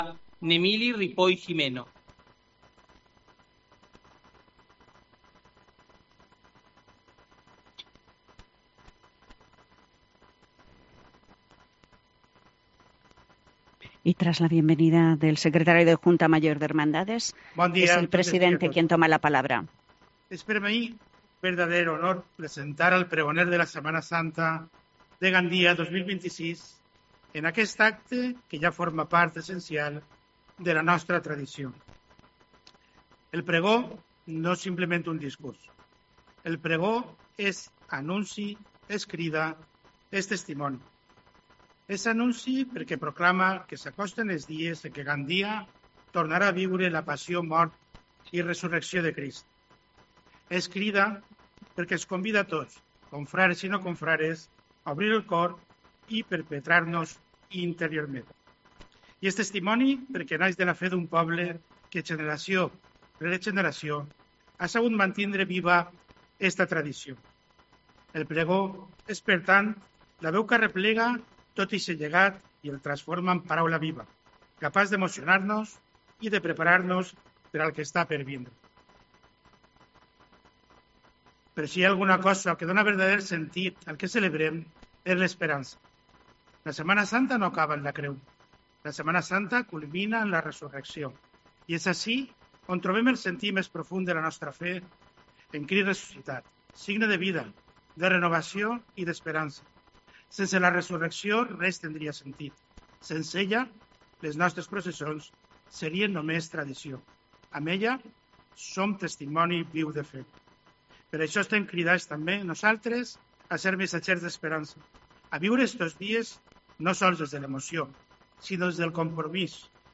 Tras la tamborrada, ha tenido lugar el Pregón de la Semana Santa a cargo del Obispo Auxiliar Rvdo. Fernando Ramón, actos ambos que dan inicio a la Semana Santa de Gandia 2026